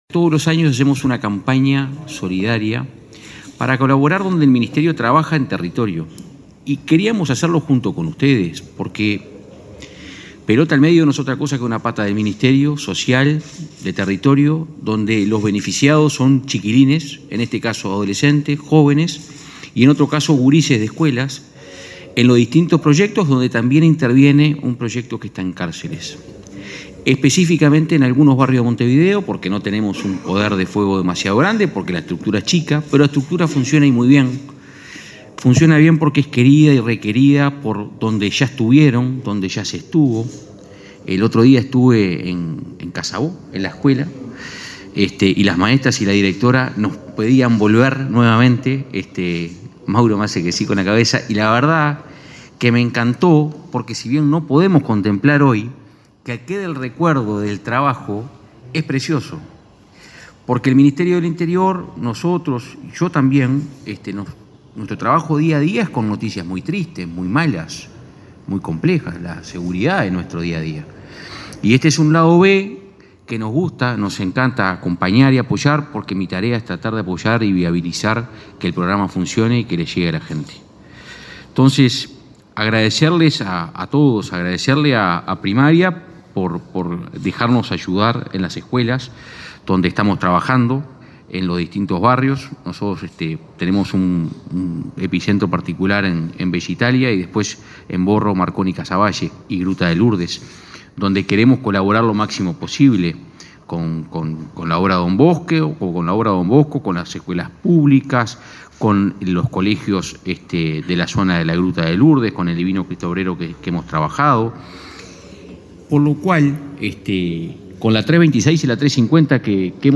Palabras de autoridades en lanzamiento de campaña “Comunidades solidarias”
El Ministerio del Interior, a través de la Dirección de Convivencia y Seguridad Ciudadana, lanzó la campaña “Comunidades solidarias” y reconoció a deportistas destacados en el marco del programa Pelota al Medio a la Esperanza. El titular de la dependencia, Santiago González, y el subsecretario nacional del Deporte, Pablo Ferrari, señalaron la importancia de la temática.